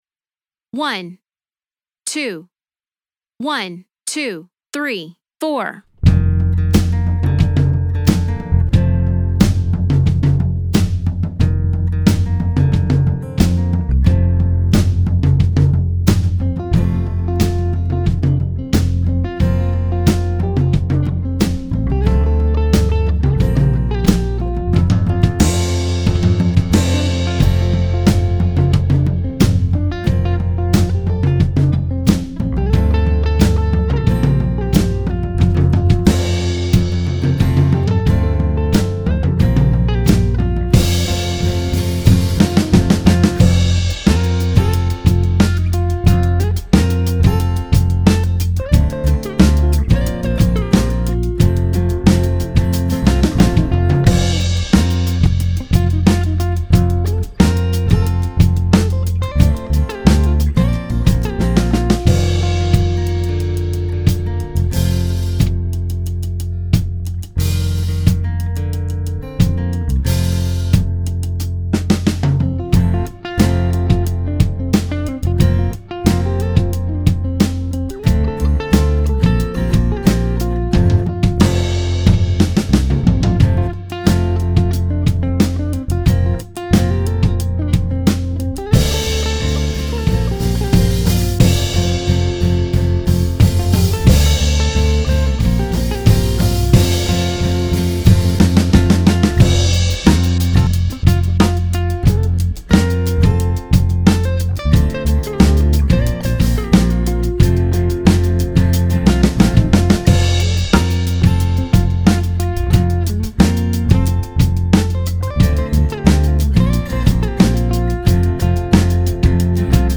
• Must be played on an electric bass guitar.